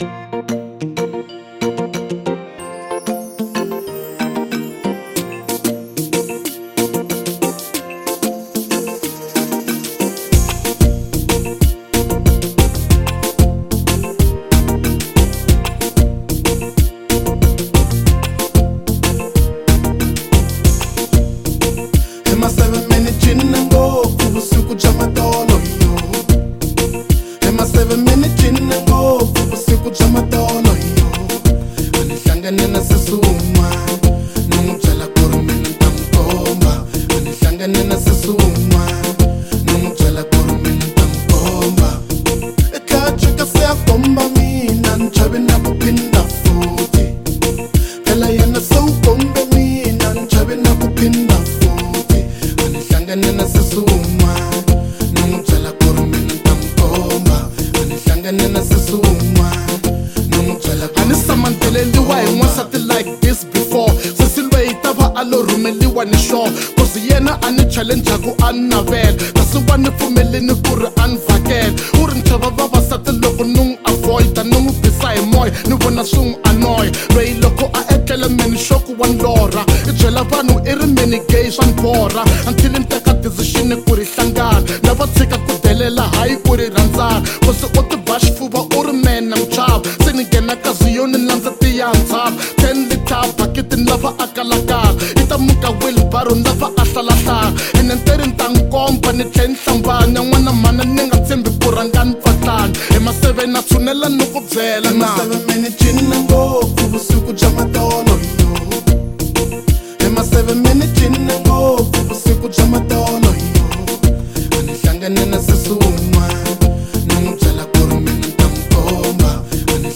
hipop